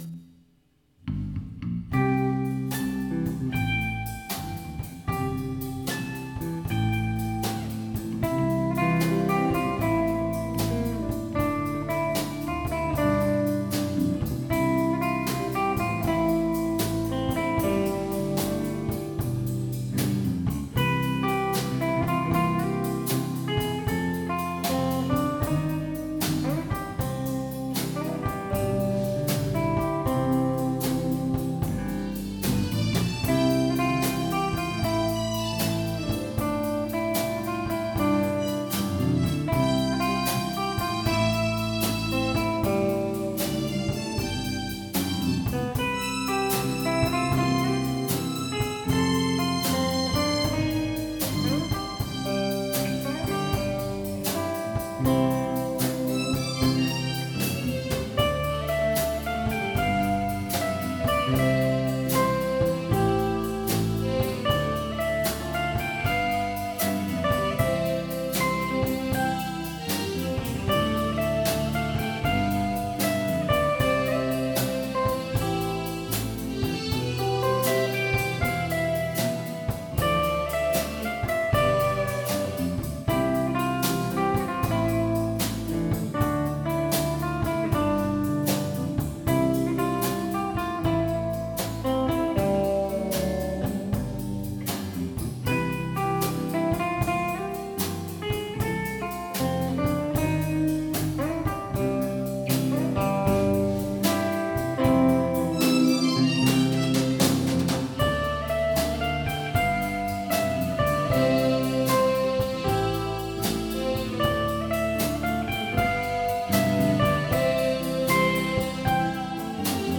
26th Live 2018 Spring Live
今年最初のライブを開催しました、今年は暖かく桜もほとんど散ってしまいましたがライブは賑やかに進み無事に開催出来ました、